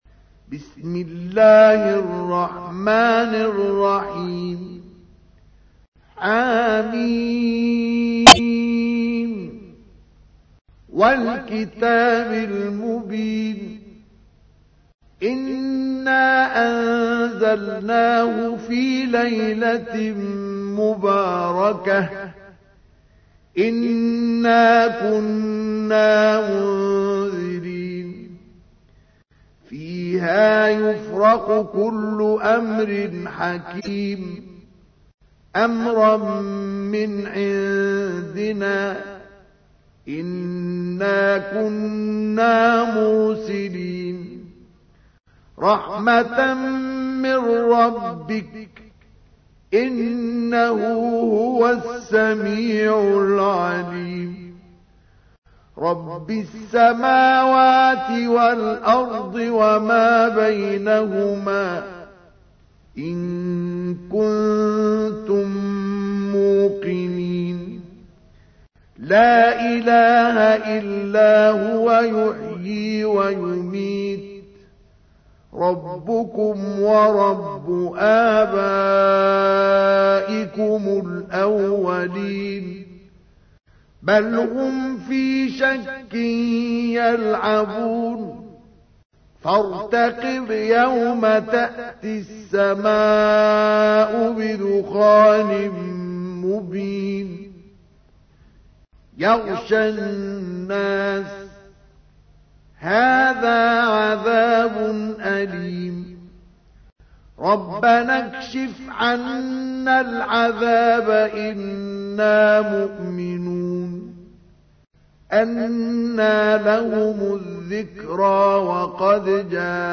تحميل : 44. سورة الدخان / القارئ مصطفى اسماعيل / القرآن الكريم / موقع يا حسين